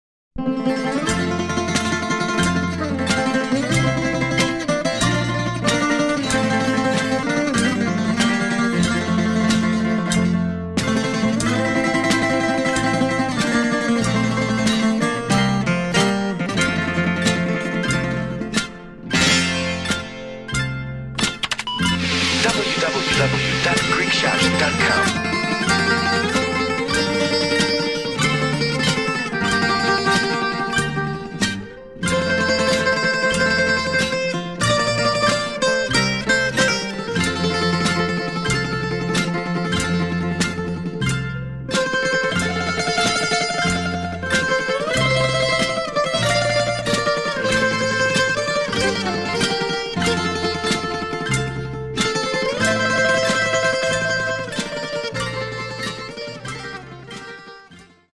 14 great instrumentals from a great composer
Bouzouki soloist